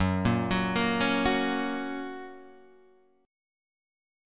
F# chord {2 1 4 3 2 2} chord